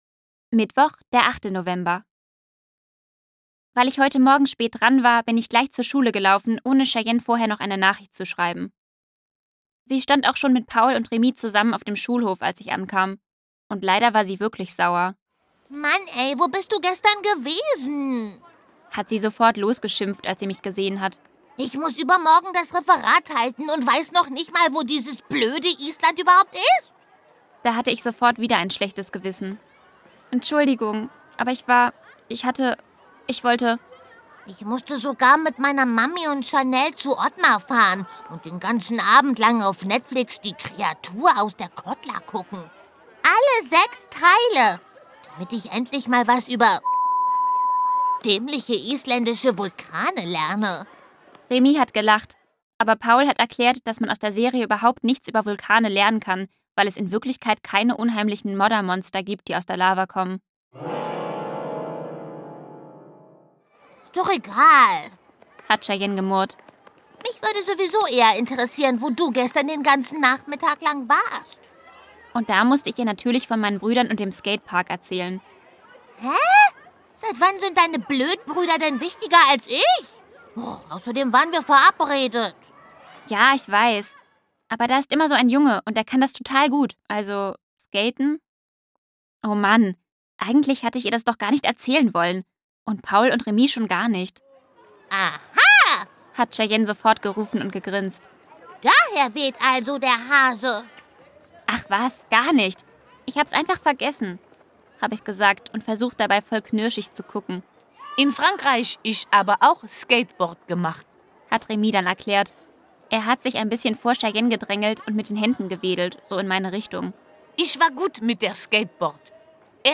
Hörbuch: Mein Lotta-Leben.